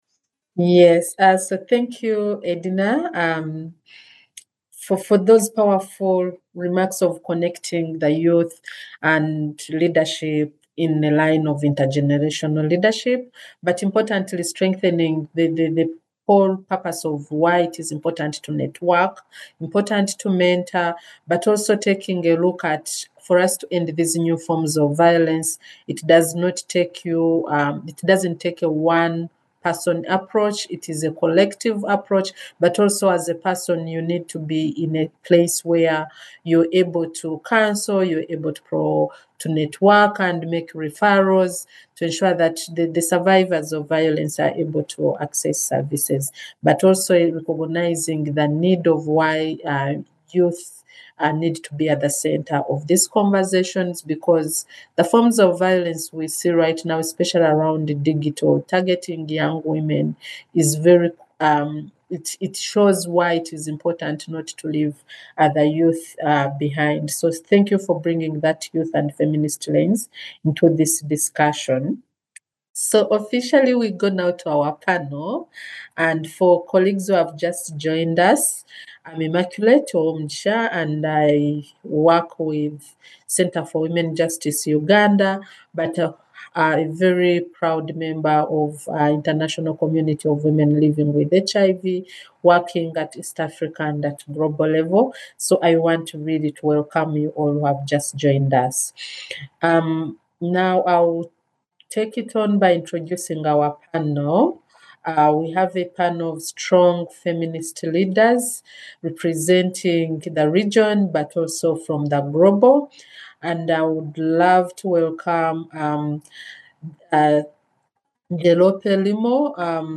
Webinar: Shifts in Ending New and Evolving Forms of Violence Part 3 – International Community of Women living with HIV Eastern Africa
Listen to Part Three conversation below;